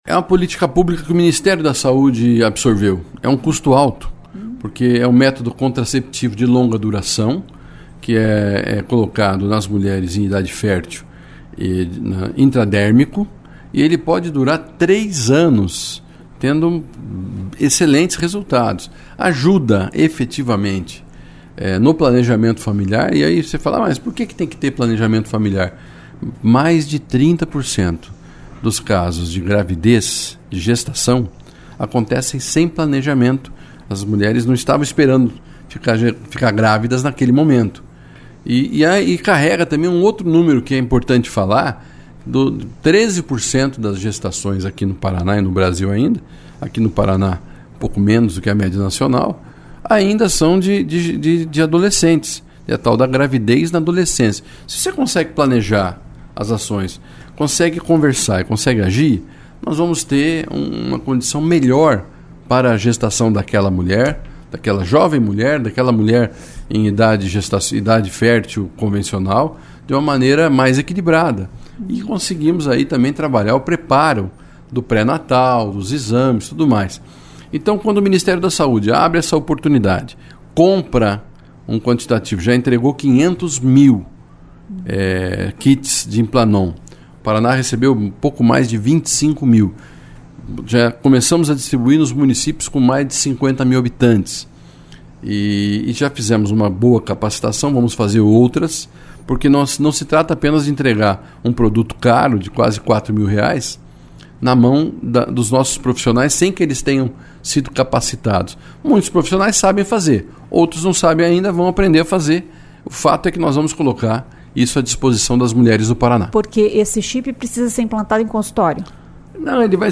O secretário de Saúde do Paraná, Beto Preto, falou sobre esta política pública.